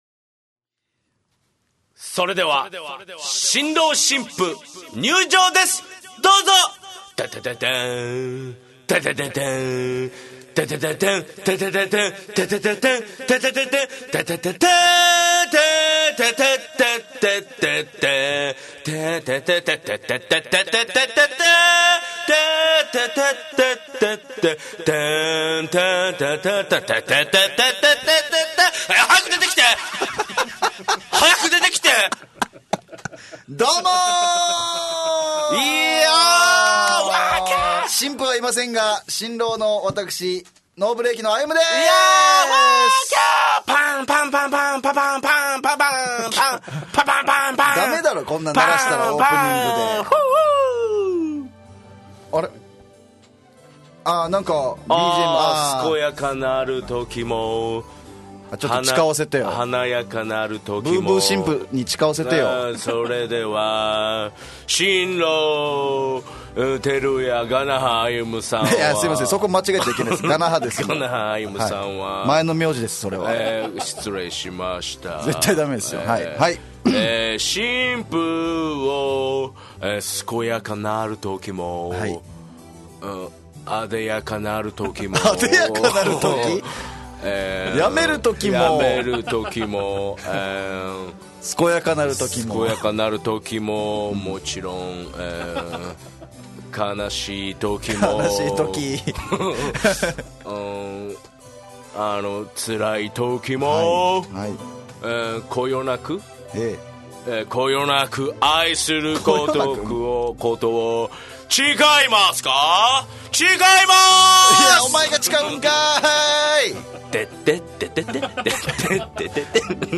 沖縄のFMラジオ局 fm那覇。